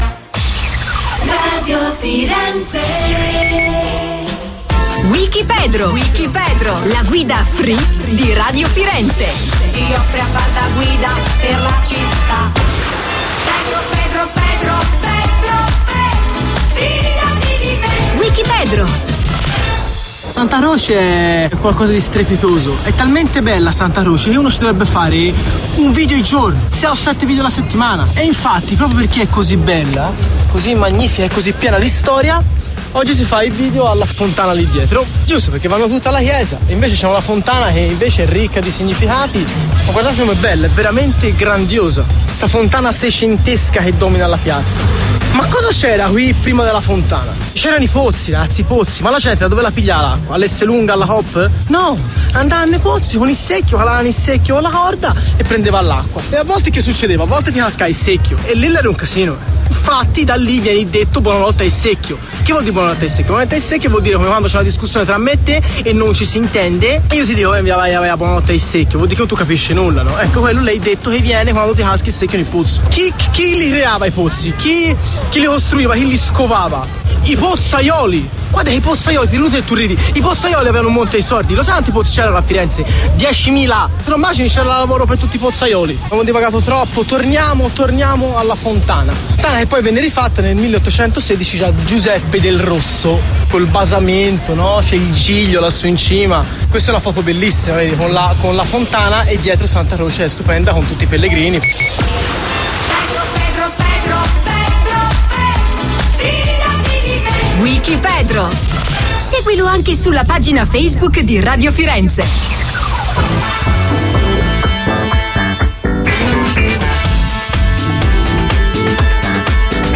Fontana Piazza Santa Croce A Firenze
Fontana Piazza Santa Croce a Firenze.mp3